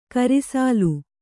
♪ kari sālu